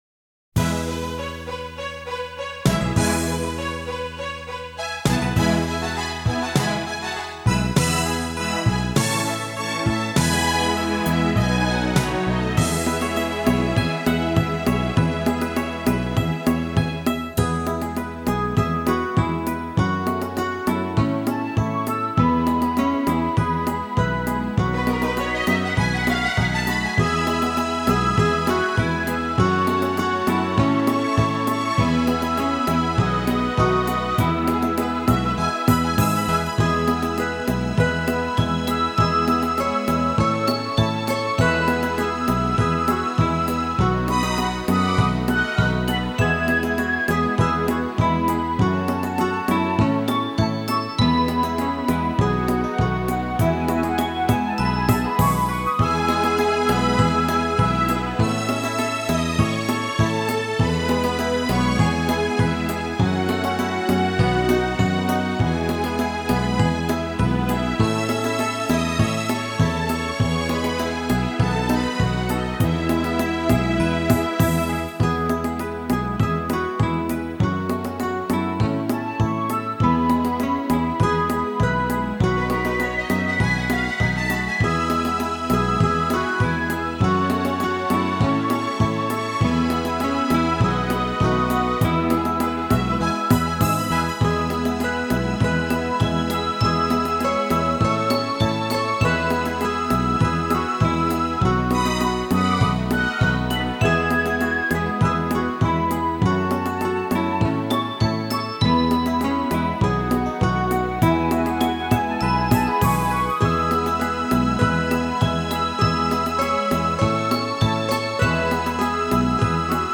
F调4/4
韩国佛曲
吉他
二胡
笛子
扬琴